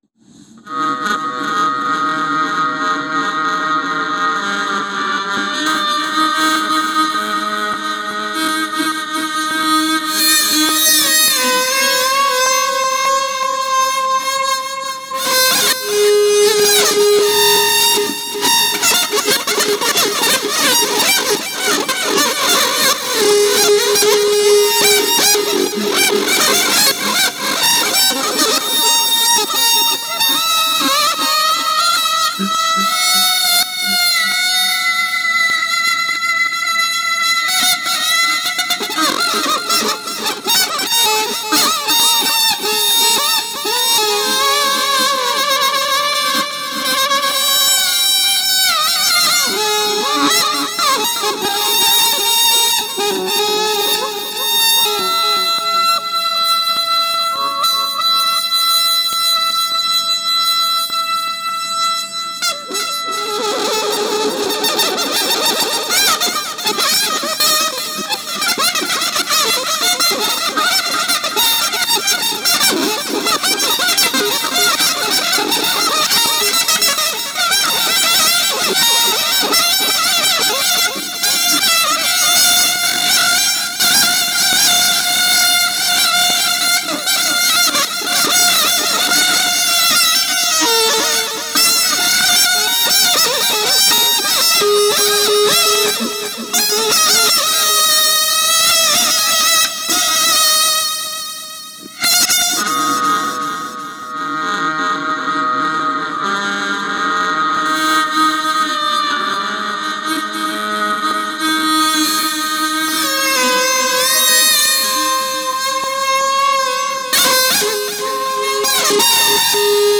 これは縦笛の様な外観ですが、サックスのようにシングル・リードを発音体とするもの。
そのため、まるでリコーダーのような高速のタンギングが可能になっています（これは本作中でも多用されています）。
リード楽器である事の特徴を非常に強調した、倍音を豊富に含んだ音色コントロールも美しく魅力的です。
本作は楽器音をマイキングし、エフェクト処理して、そのまま録音した無伴奏管楽器独奏アルバムです。